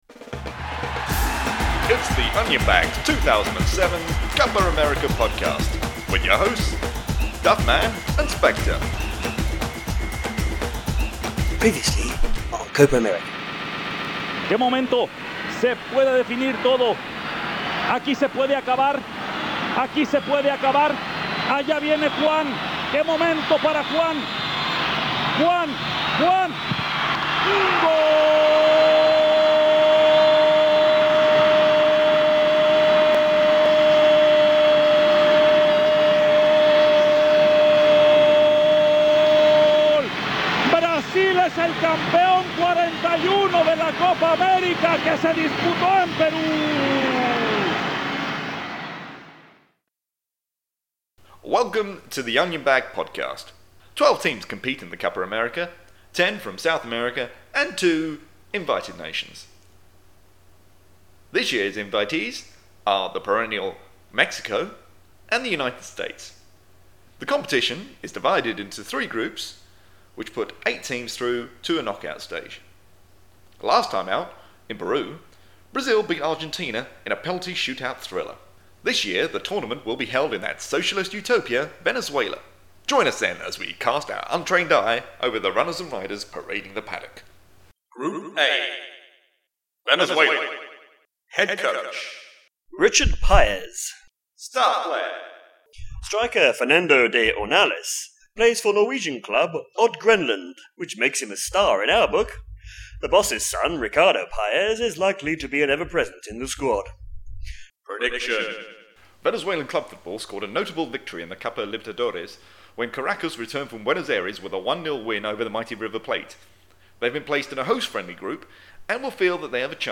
The Onion Bag's Copa America 2007 Podcast (File size: 10.75Mb, Length: 11m45s) WARNING: Contains scenes of giggling and ineptitude.